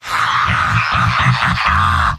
Giant Robot lines from MvM. This is an audio clip from the game Team Fortress 2 .
Heavy_mvm_m_laughhappy02.mp3